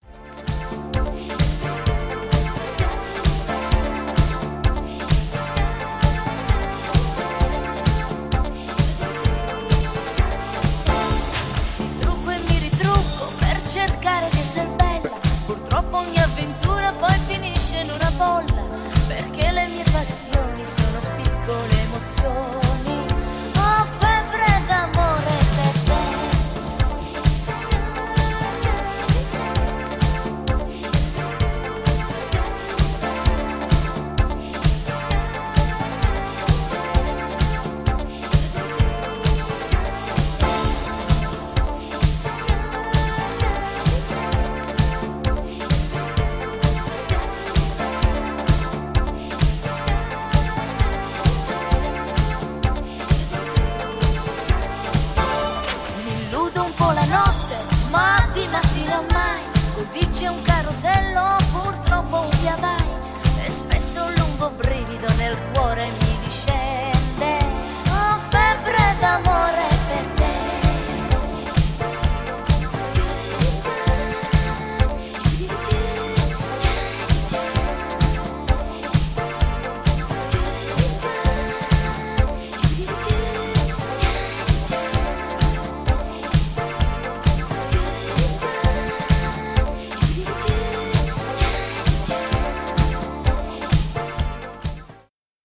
ジャンル(スタイル) DISCO / NU DISCO / RE-EDIT